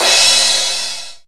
NY HI CRASH.wav